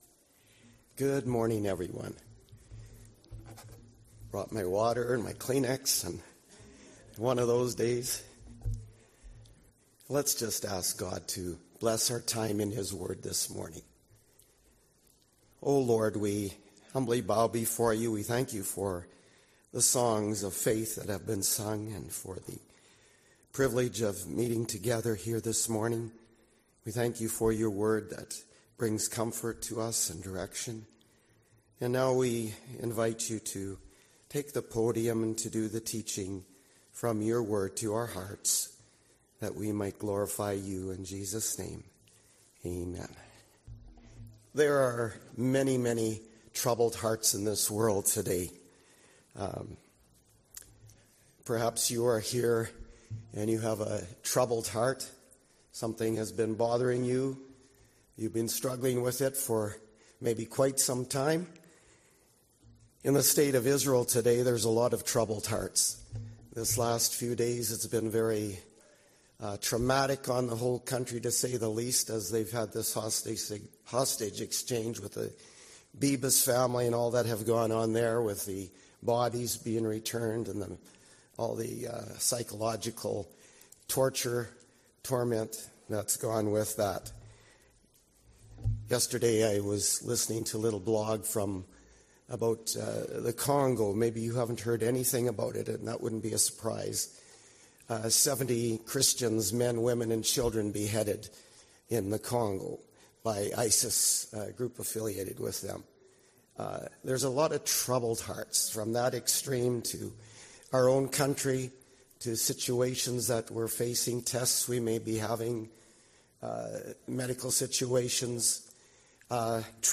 Sermon 4 Answers for Troubled Hearts